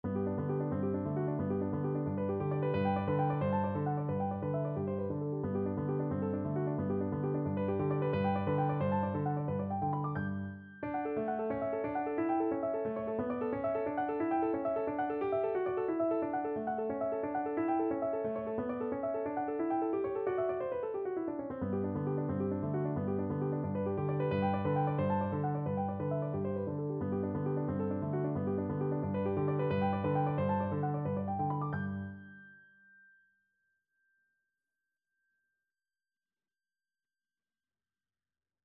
No parts available for this pieces as it is for solo piano.
G major (Sounding Pitch) (View more G major Music for Piano )
4/4 (View more 4/4 Music)
Allegro vivace (=178) (View more music marked Allegro)
Piano  (View more Intermediate Piano Music)
Classical (View more Classical Piano Music)